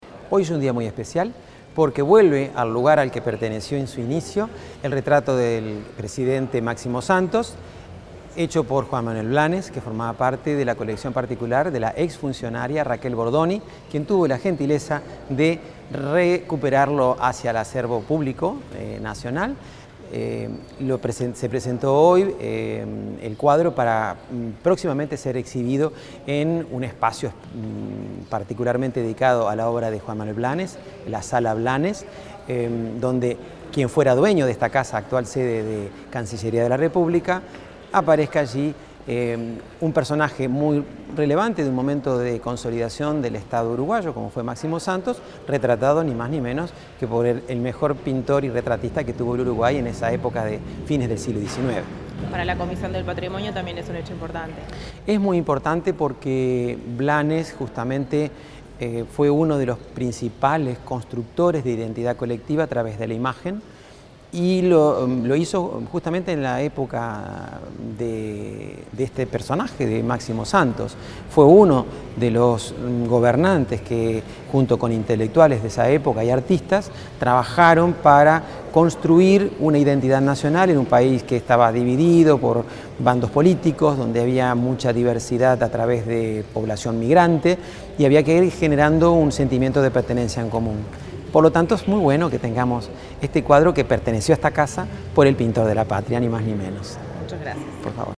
Declaraciones del director de la Comisión de Patrimonio, Marcel Suárez
El director general de la Comisión del Patrimonio, Marcel Suárez, dialogó con la prensa tras participar en la donación de un retrato del expresidente